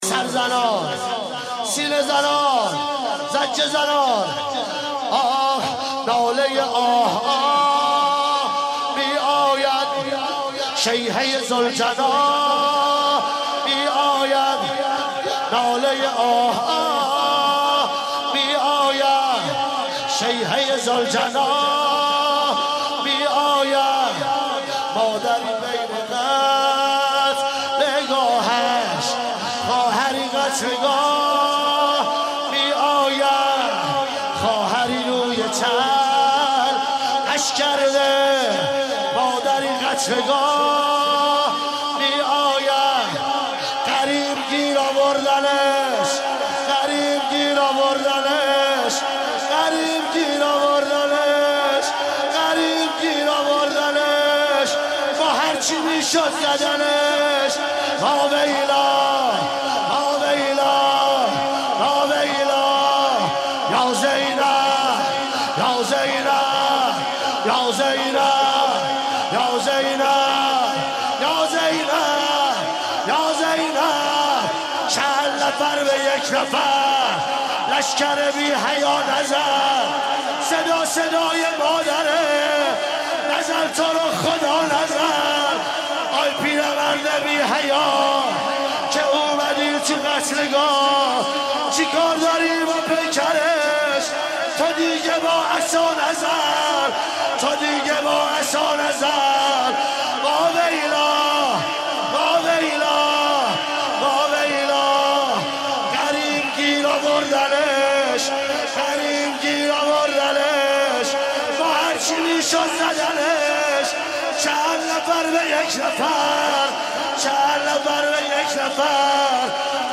فاطمیه 95
شور مداحی